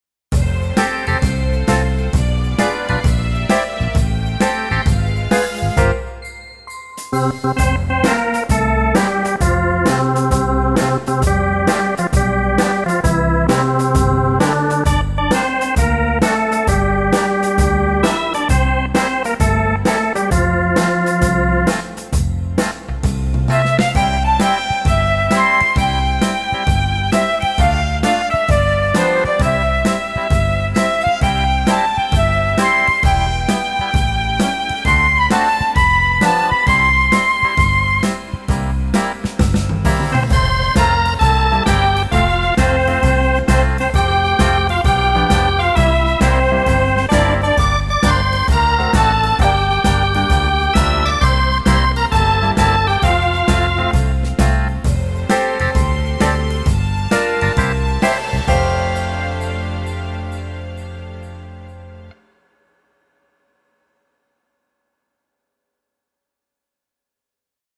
Musikbeispiel